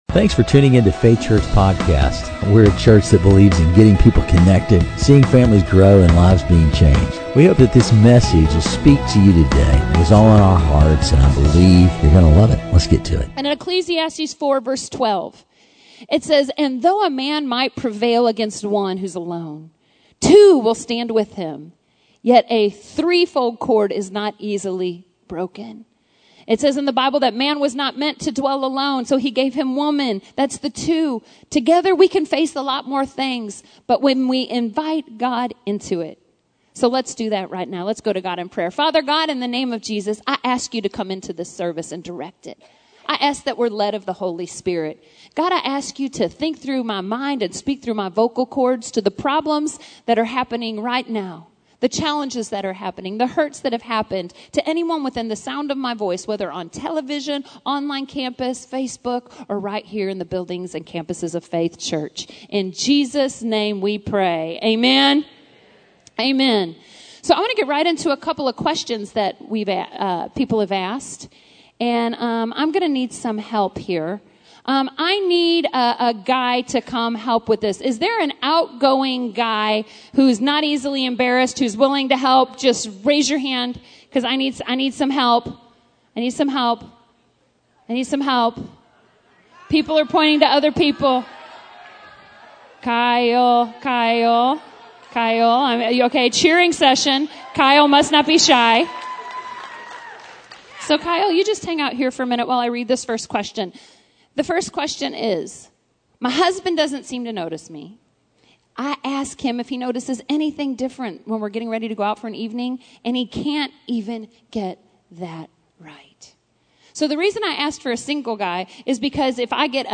What’s really going in a woman’s head when she asks? In this hilarious and engaging message